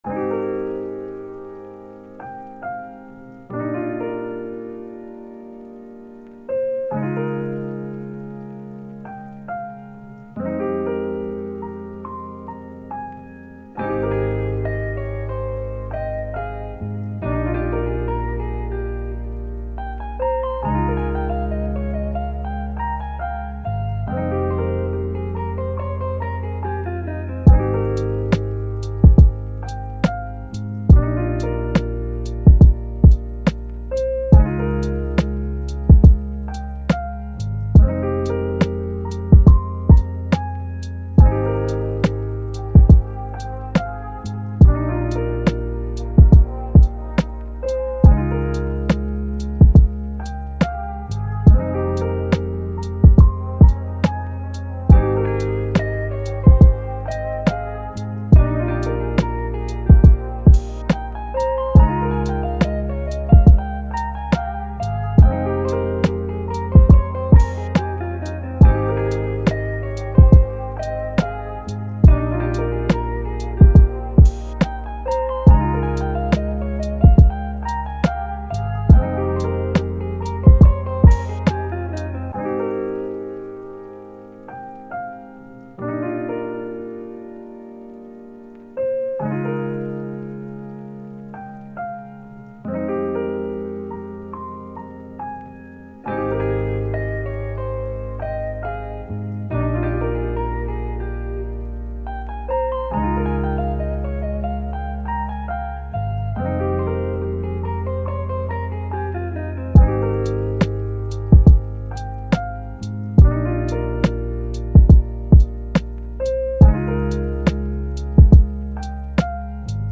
دانلود بیت لوفای هیپ هاپ شماره 121
ژانر : لوفای مود : چیل | هیپ هاپ تمپو : 140 زمان